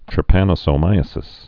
(trĭ-pănə-sō-mīə-sĭs)